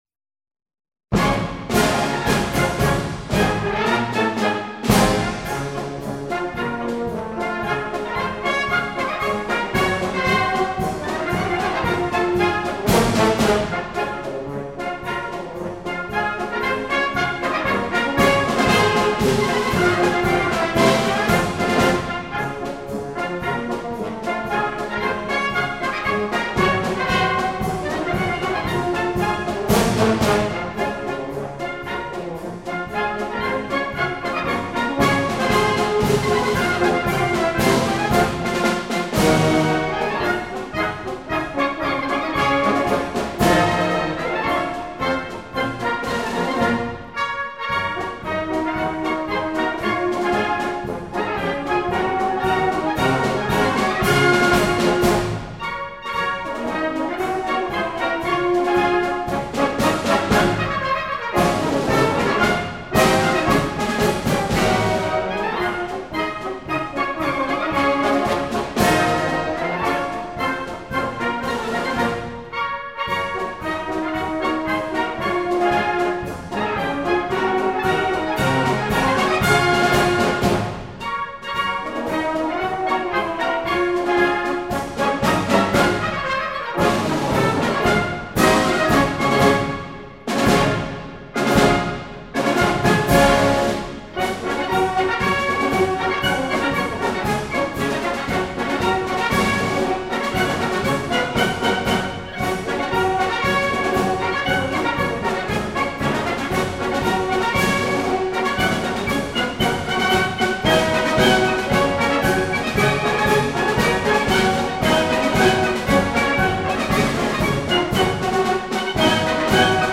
Советский марш